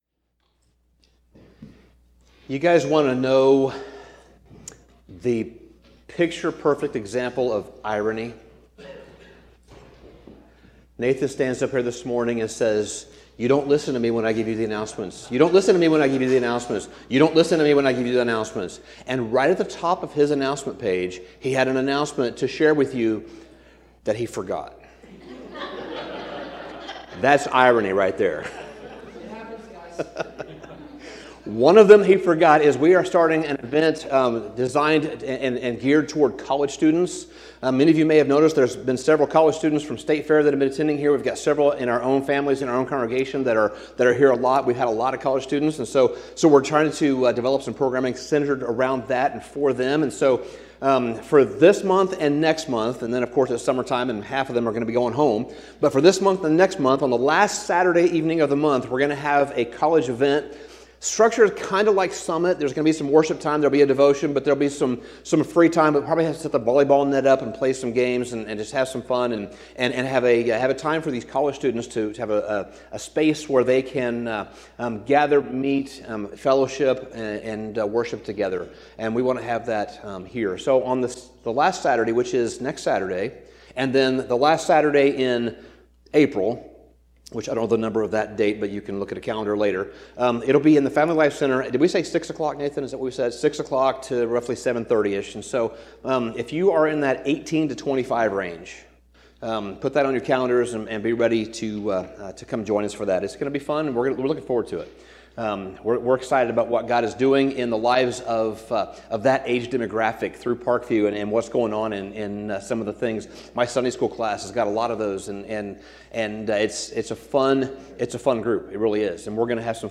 Sermon Summary Paul’s prayer in Ephesians 1 draws us into a posture before it ever gives us a perspective.